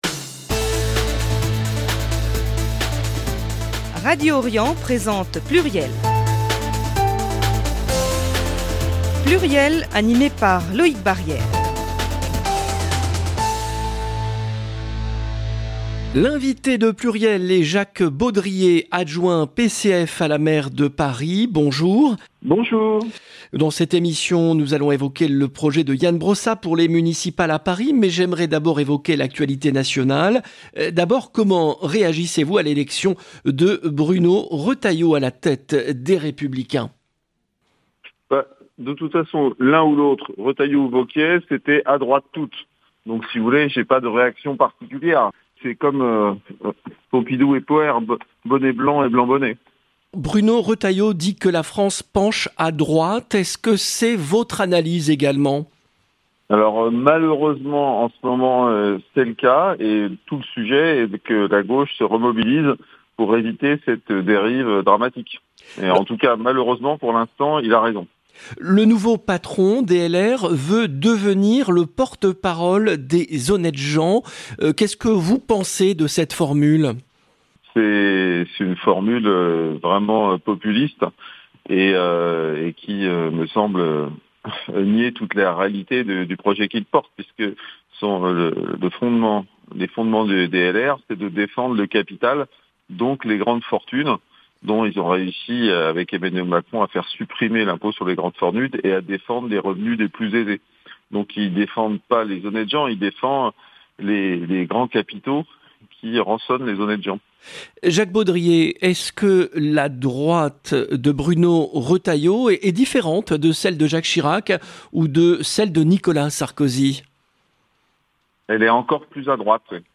L'invité de PLURIEL est Jacques Baudrier, adjoint PCF de la maire de Paris, en charge du Logement. Parmi les sujets évoqués dans cette émission : L'élection de Bruno Retailleau à la tête de LR Les propositions d'Emmanuel Macron pour réformer le financement de la protection sociale Le refus du chef de l'Etat d'organiser un référendum sur les retraites Les projets du PCF pour les municipales à Paris 0:00 15 min 6 sec